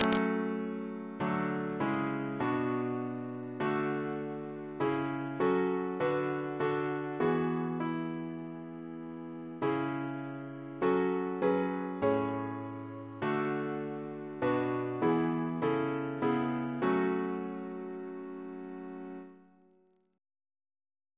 Double chant in F♯ minor Composer: Bryan Hesford (1930-1996) Reference psalters: ACP: 4